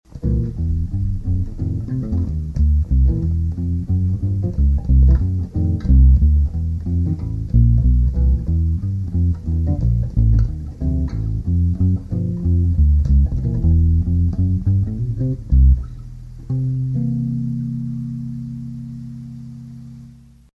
Yes, I'm using my thumb plus i and m for the 3rds and 7ths.